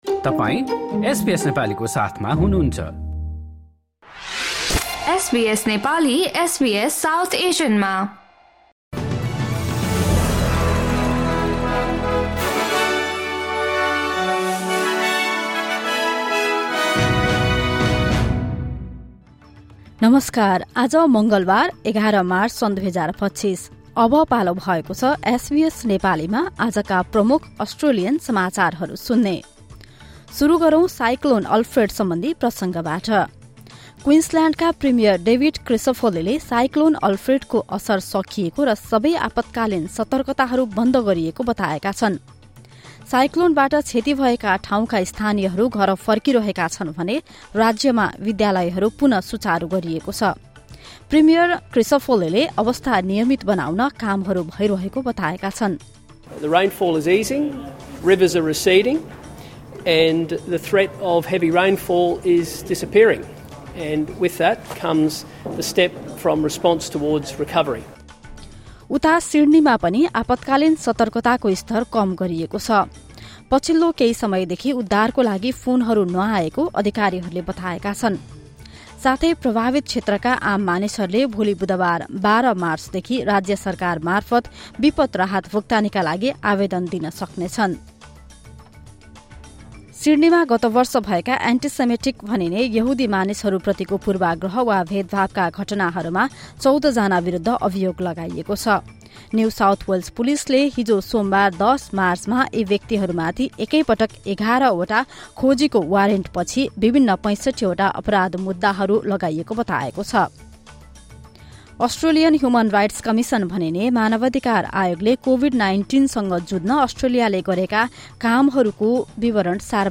SBS Nepali Australian News Headlines: Tuesday, 11 March 2025